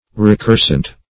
Search Result for " recursant" : The Collaborative International Dictionary of English v.0.48: Recursant \Re*cur"sant\ (r?*k?r"sant), a. [L. recursans, -antis, p. pr. of recursare to run back, v. freq. of recurrere.